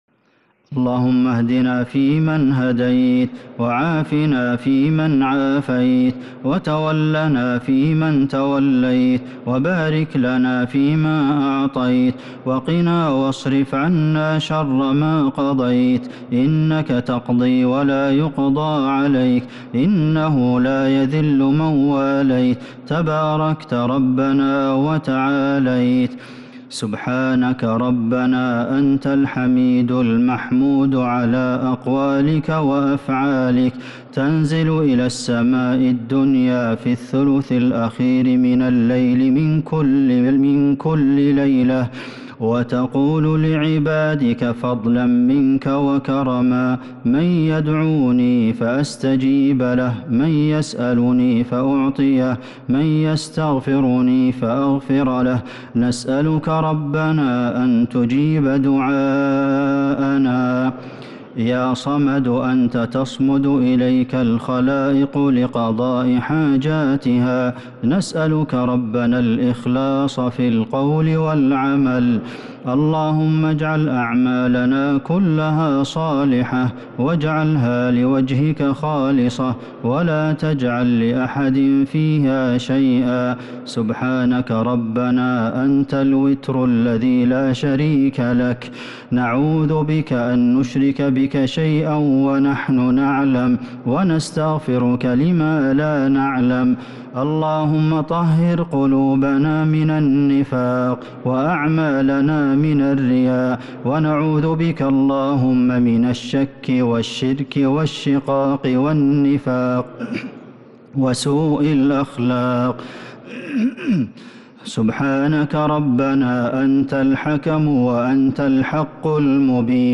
دعاء القنوت ليلة 28 رمضان 1443هـ | Dua for the night of 28 Ramadan 1443H > تراويح الحرم النبوي عام 1443 🕌 > التراويح - تلاوات الحرمين